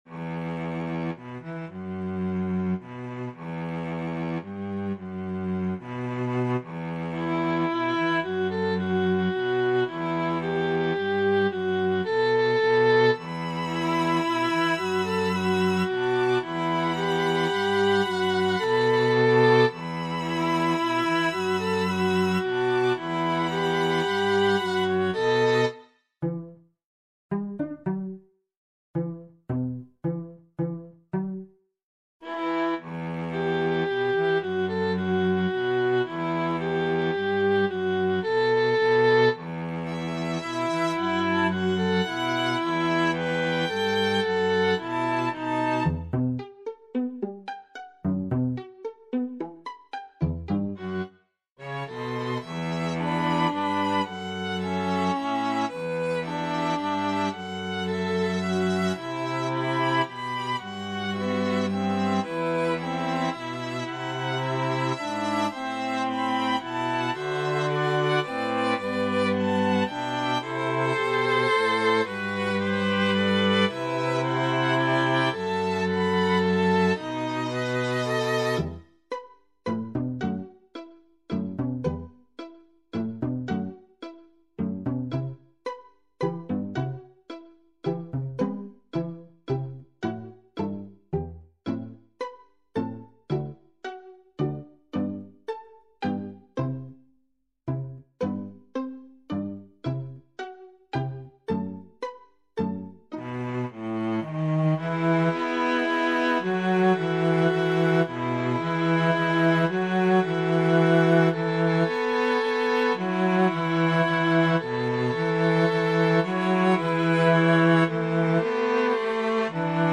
Strings Quartet